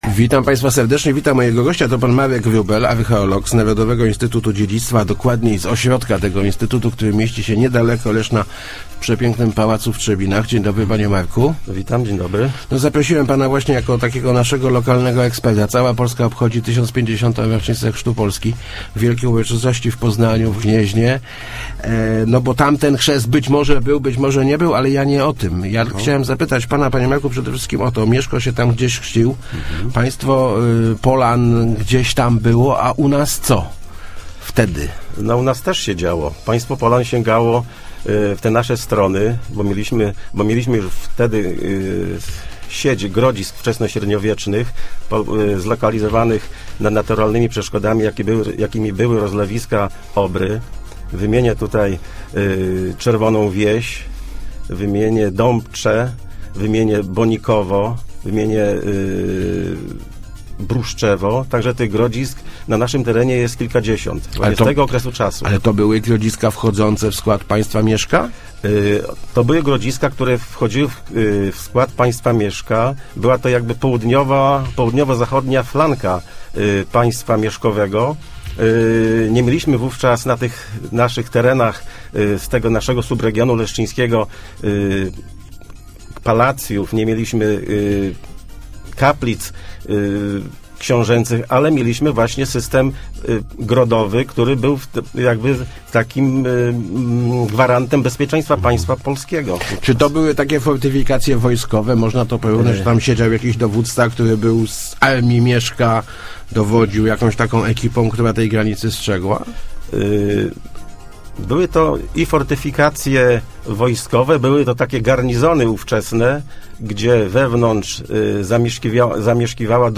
Start arrow Rozmowy Elki arrow Chrzest Mieszka a Leszno